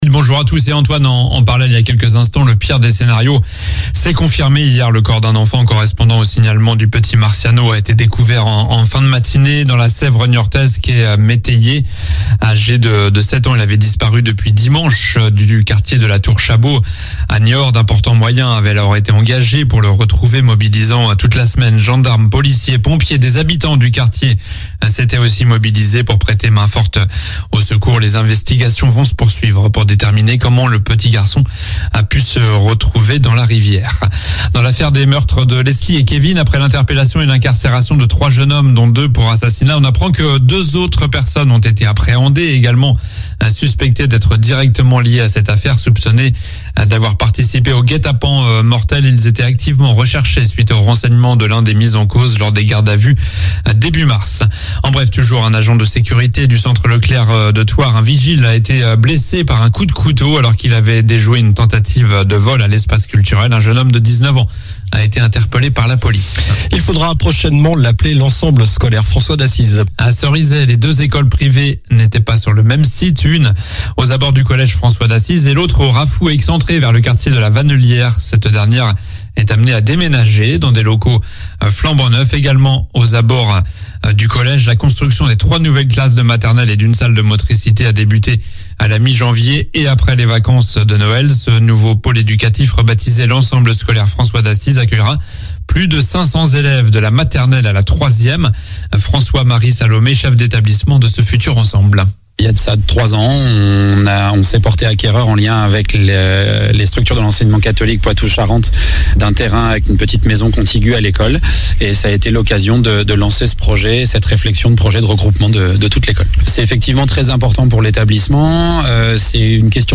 Journal du samedi 15 avril (matin)